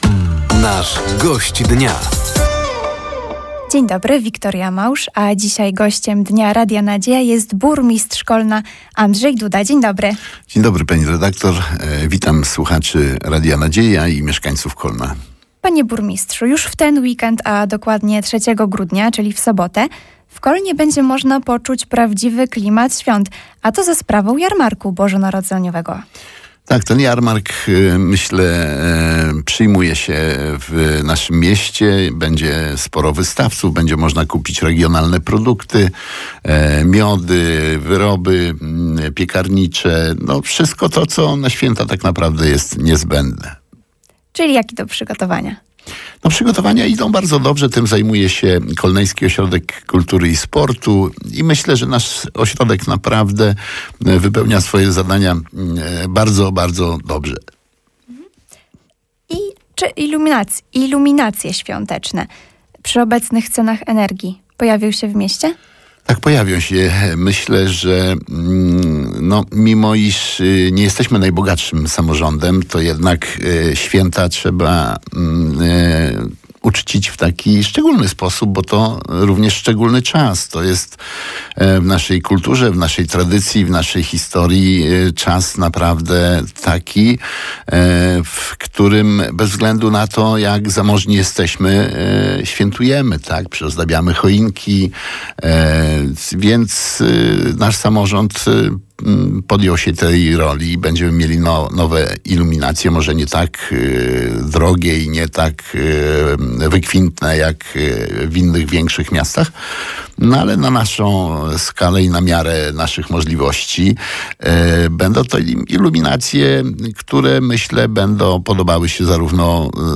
Gościem Dnia Radia Nadzieja był Burmistrz Kolna, Andrzej Duda. Tematem rozmowy był między innymi projekt budżetu na przyszły rok czy planowane inwestycje. Gość Dnia opowiedział także o zbliżającym się w Kolnie Jarmarku Bożonarodzeniowym oraz Sylwestrze.